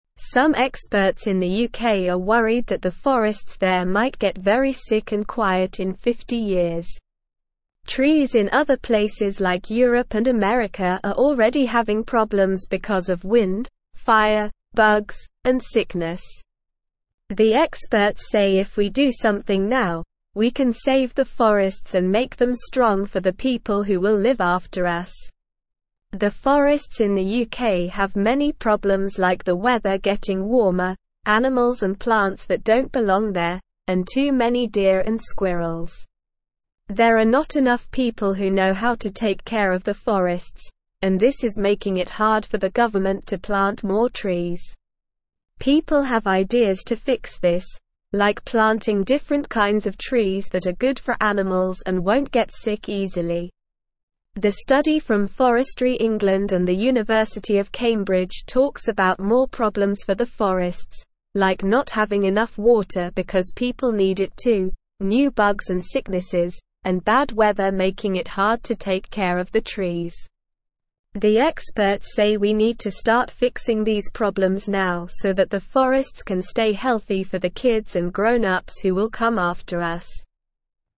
Slow
English-Newsroom-Lower-Intermediate-SLOW-Reading-Smart-People-Want-to-Save-the-Forests.mp3